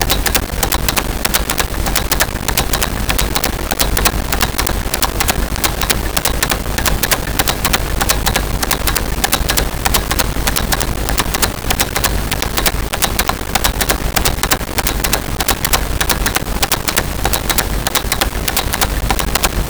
Clock Ticking 1
clock-ticking-1.wav